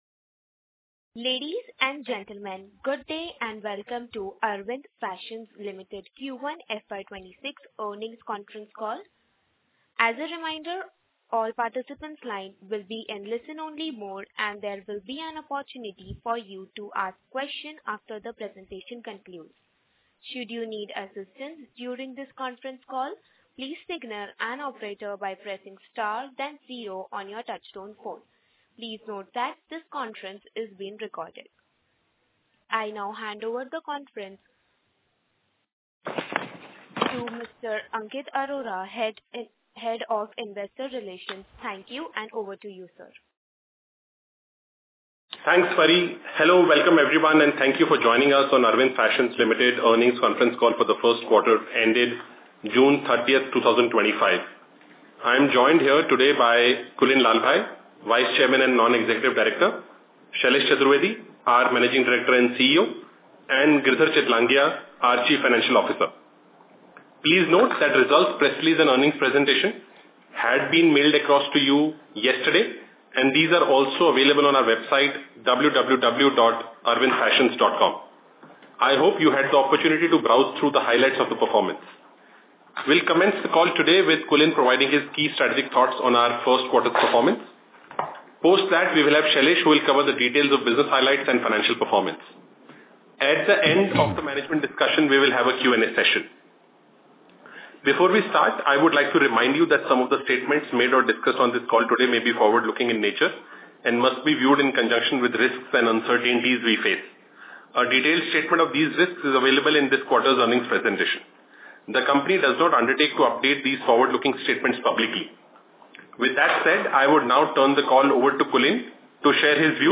Concalls
Arvind-Fashions-Limited-–-Q1-FY26-earnings-conference-call.mp3